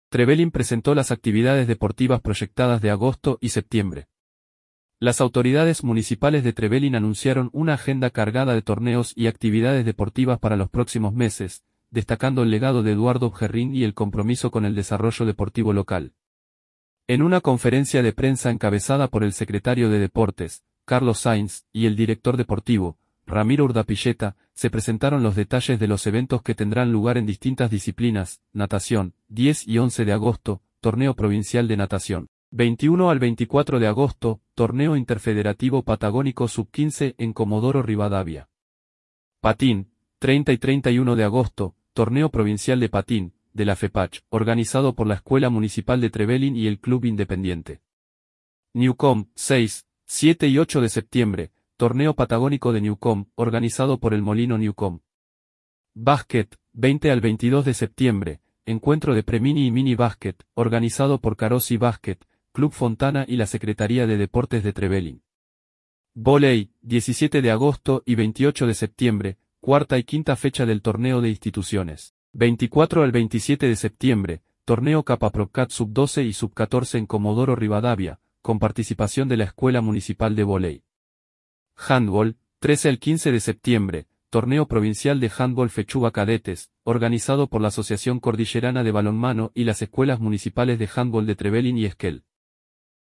En una conferencia de prensa